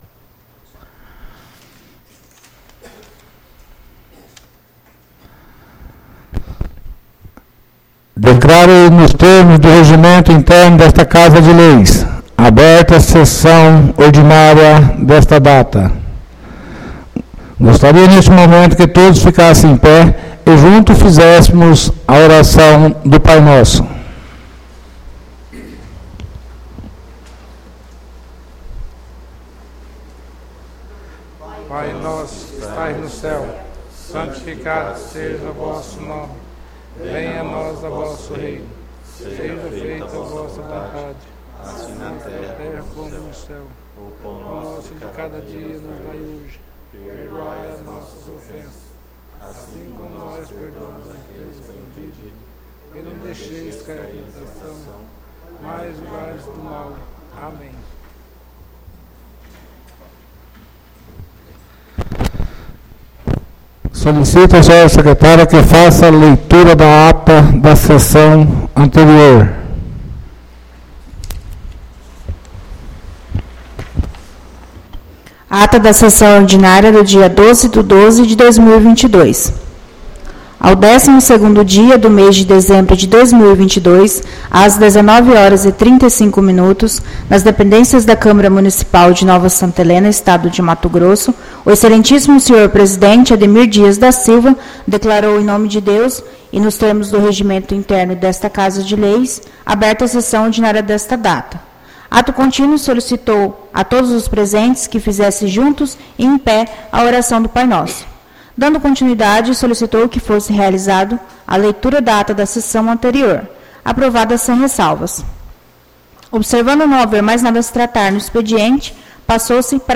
ÁUDIO SESSÃO 19-12-22 — CÂMARA MUNICIPAL DE NOVA SANTA HELENA - MT
Sessões Plenárias